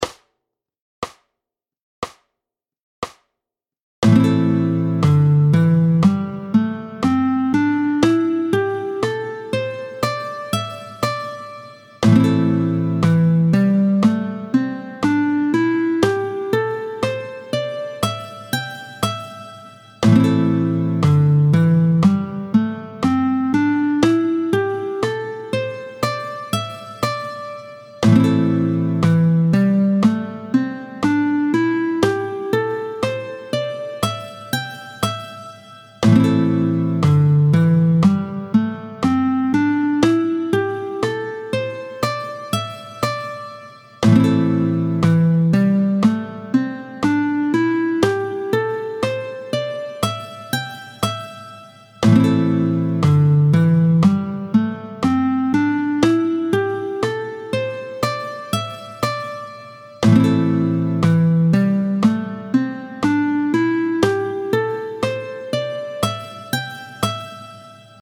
32-02 Lam doigtés 3 et 4, tempo 60